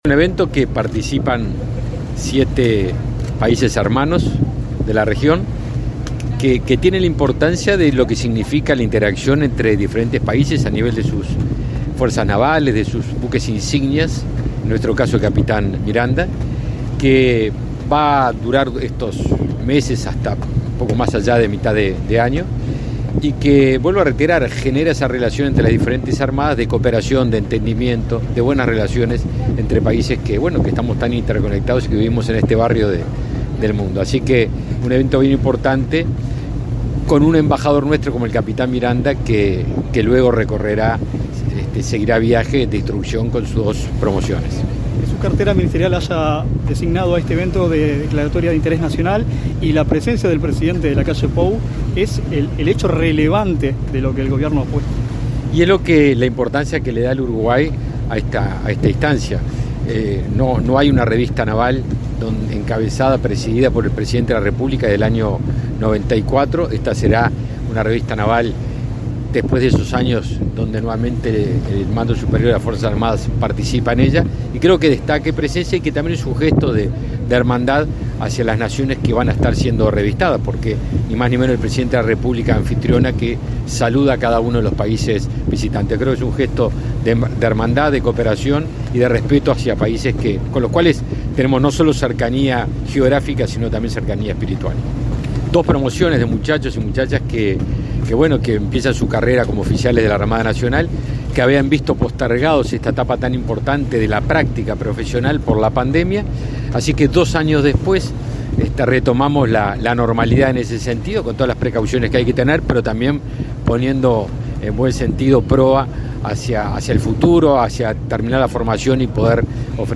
Entrevista al ministro de Defensa, Javier García
El ministro de Defensa, Javier García, dialogó con Comunicación Presidencial sobre la importancia del desfile naval realizado este jueves 3, en el